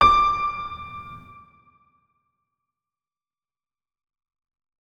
328d67128d Divergent / mods / Hideout Furniture / gamedata / sounds / interface / keyboard / piano / notes-62.ogg 57 KiB (Stored with Git LFS) Raw History